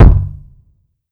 Disrespectful Kick.wav